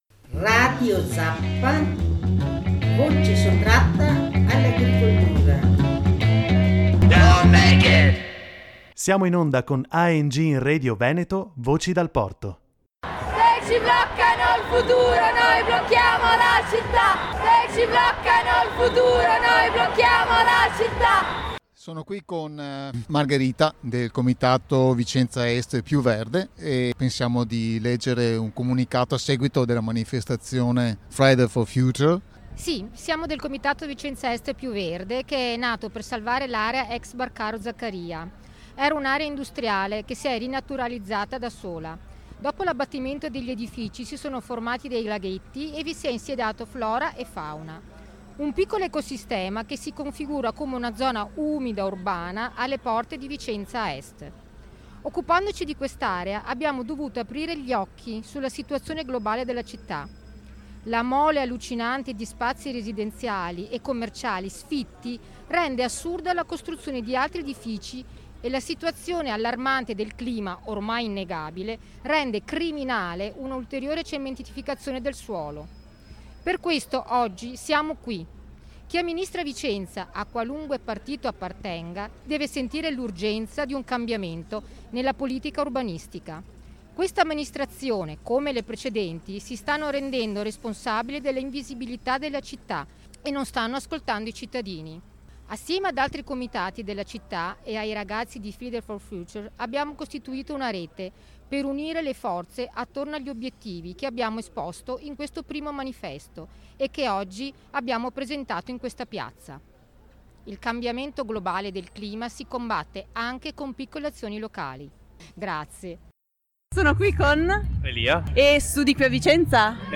Ecofatto #3 - Quarto sciopero globale Vicenza, 29 novembre 2019 Se ci bloccano il futuro noi blocchiamo la città. Al Quarto sciopero globale per il clima di Vicenza hanno partecipato diverse associazioni: Comitato Vicenza Est Più Verde, Comitato Pomari, Comitato Vicenza Est, Collettivo Ambiente e Territorio, Fridays For Future Vicenza, Futuro Fogazzaro, Comitato Popolare Ferrovieri. Ecco il Manifesto che hanno presentato pubblicamente, di fronte al Comune e ai cittadini, e alcune interviste a manifestanti e associazioni.